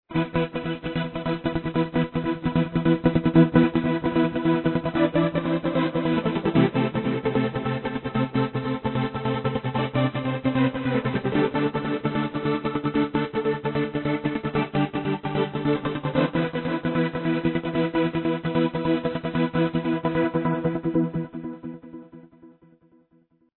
Amazing epic tune... I tried re-creating what I remember...